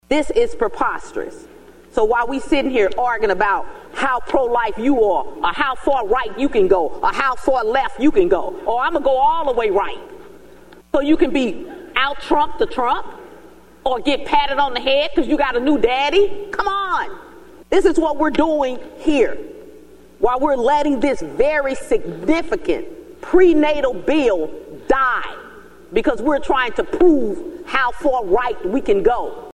That’s when Senator Karla May, a Democrat from St. Louis, interrupted to speak her mind.